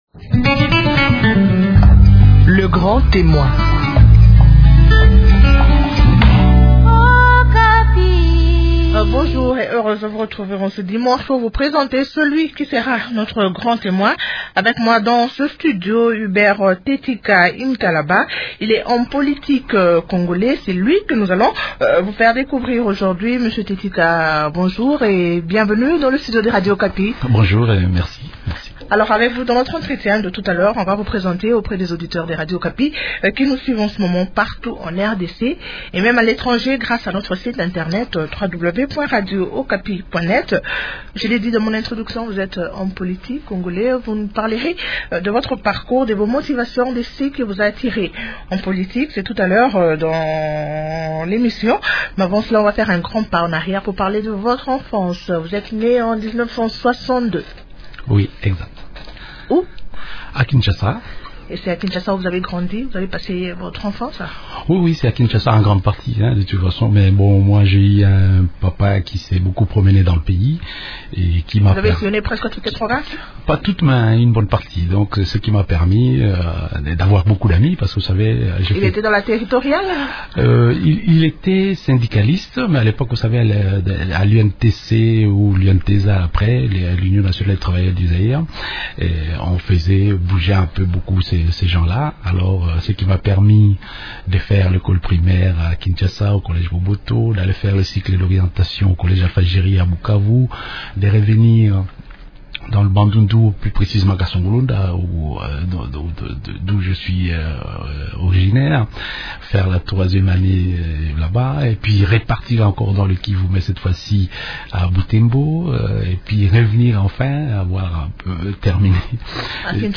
Hubert Thetika parle de la dissidence au sein de son parti. Il donne aussi dans cet échange son point de vue sur la tenue du dialogue politique.